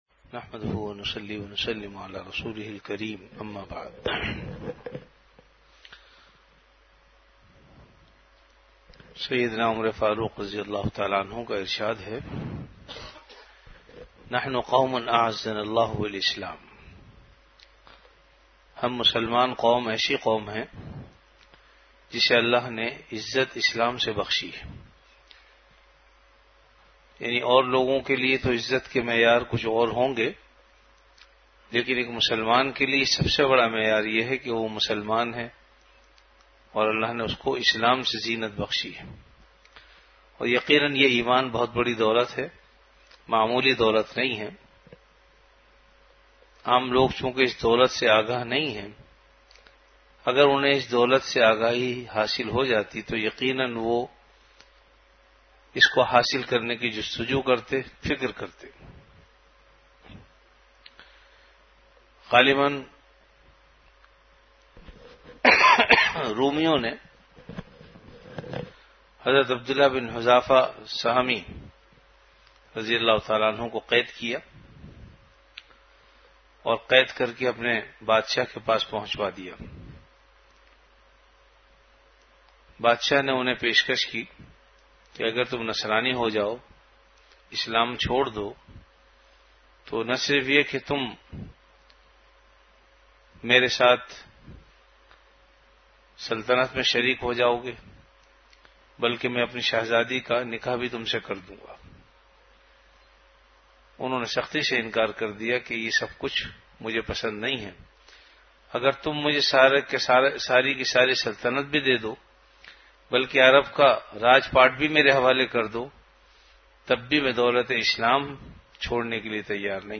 Delivered at Home.
Majlis-e-Zikr